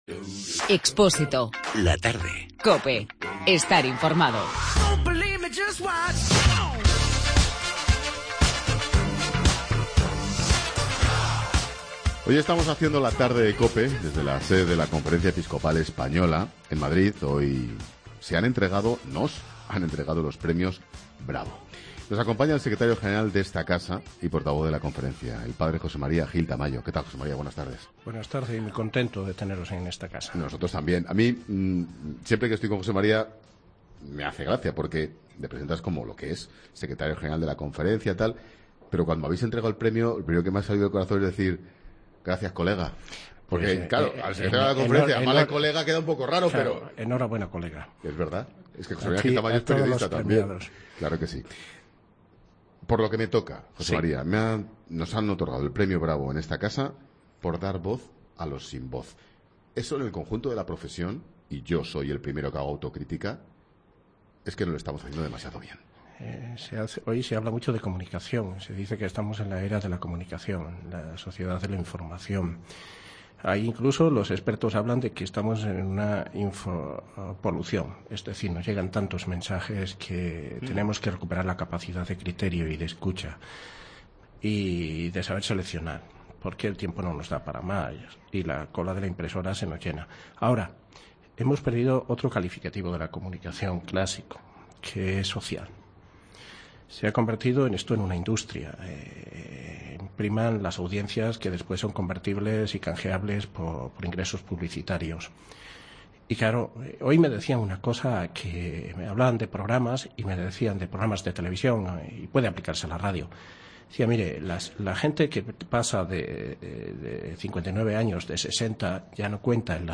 'La Tarde' de Expósito se ha desplazado a la Conferencia Episcopal Española para entrevistar su Secretario General, José María Gil Tamayo.